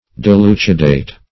Meaning of dilucidate. dilucidate synonyms, pronunciation, spelling and more from Free Dictionary.
Search Result for " dilucidate" : The Collaborative International Dictionary of English v.0.48: Dilucidate \Di*lu"ci*date\, v. t. [L. dilucidatus, p. p. of dilucidare.]